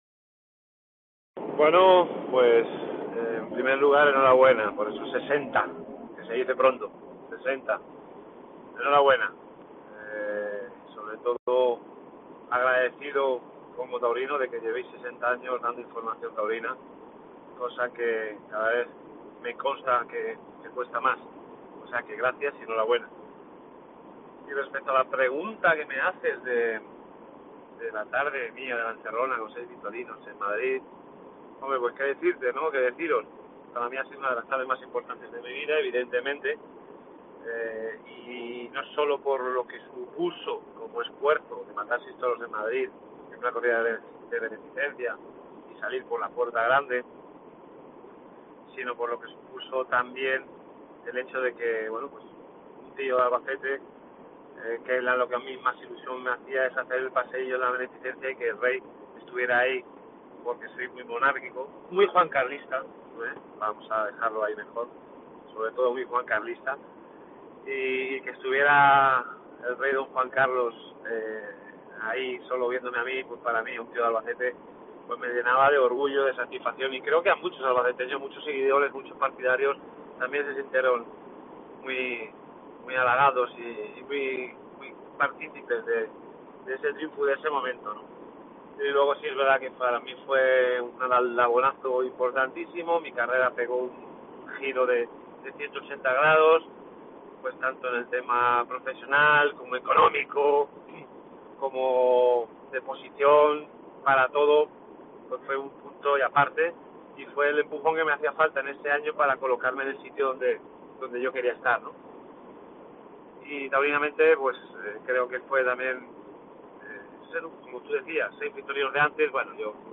El diestro Manuel Caballero, en COPE Albacete